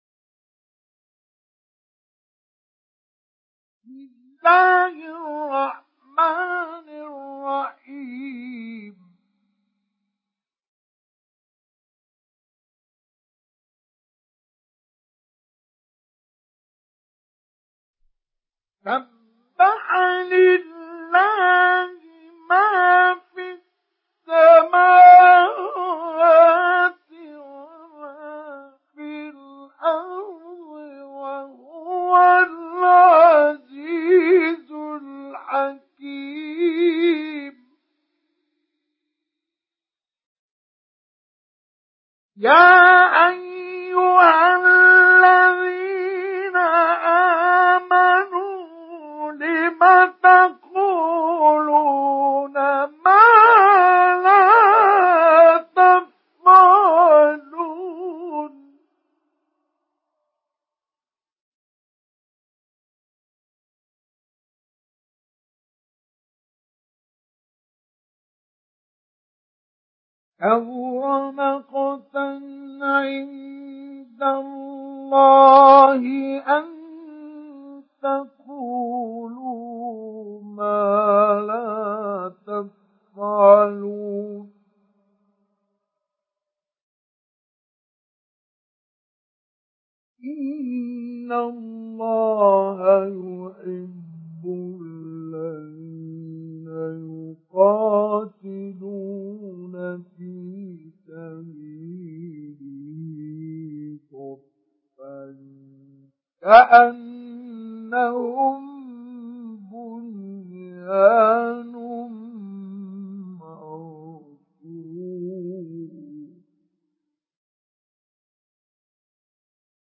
Surah Saf MP3 in the Voice of Mustafa Ismail Mujawwad in Hafs Narration
Surah Saf MP3 by Mustafa Ismail Mujawwad in Hafs An Asim narration.